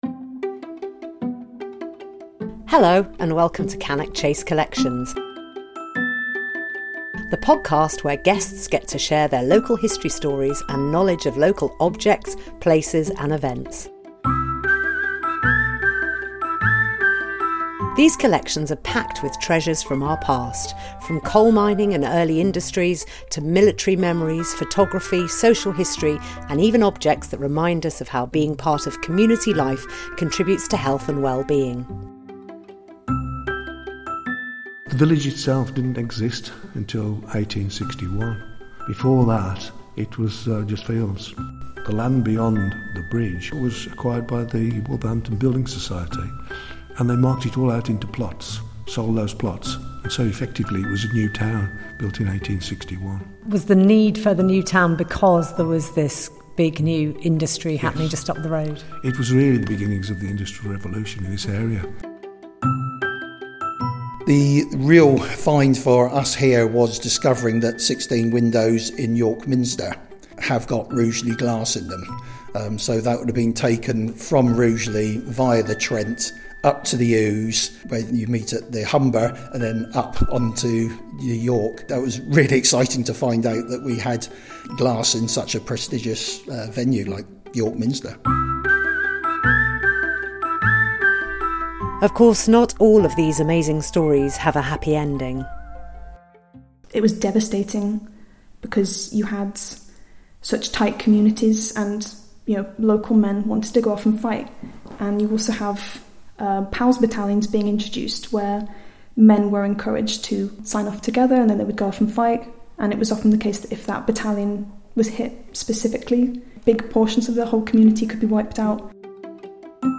Discover the stories behind the places, people, and objects that shaped Cannock Chase. In this new podcast series, local voices bring heritage to life — from industry and wartime stories to creativity, community, and everyday memories.